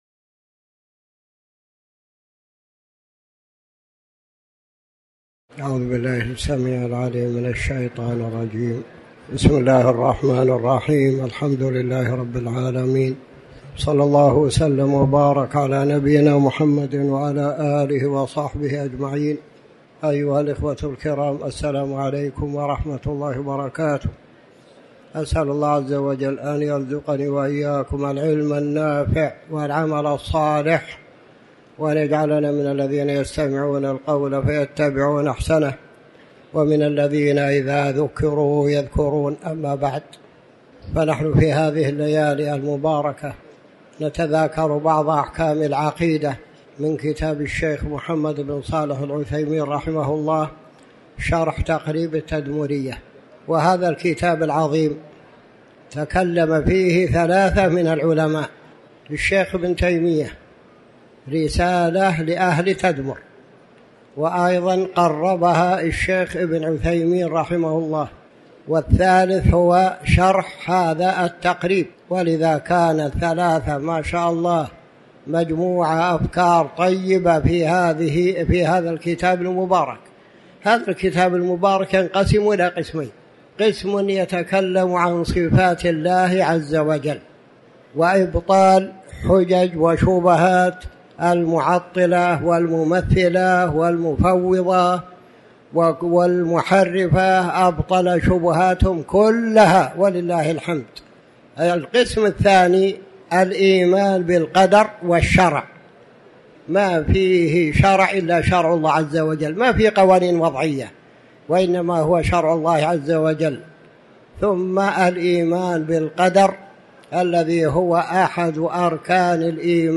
تاريخ النشر ١٦ ربيع الثاني ١٤٤٠ هـ المكان: المسجد الحرام الشيخ